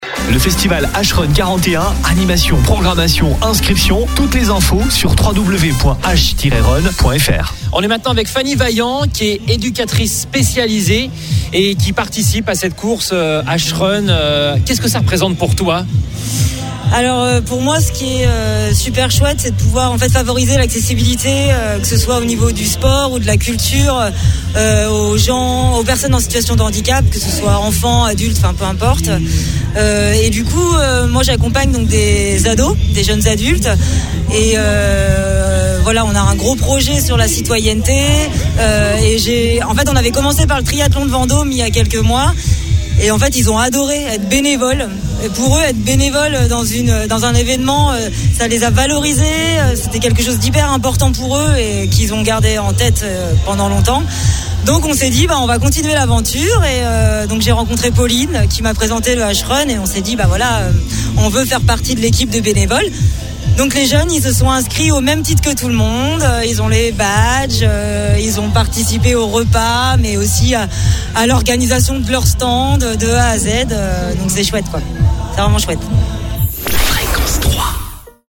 Pour cette occasion une partie de l’équipe de Fréquence 3 s’est déplacée pour vous faire vivre ce festival.
Et nous avons réalisé plusieurs interviews avec les organisateurs, les participants et les bénévoles pour vous faires découvrir les coulisses de cet évènement.